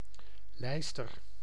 Ääntäminen
Synonyymit tourdre Ääntäminen France Tuntematon aksentti: IPA: /ɡʁiv/ Haettu sana löytyi näillä lähdekielillä: ranska Käännös Ääninäyte Substantiivit 1. lijster Suku: f .